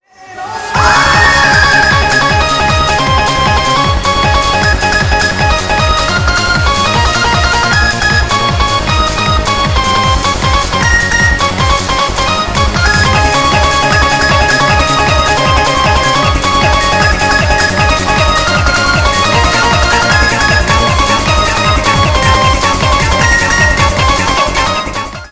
applause2.wav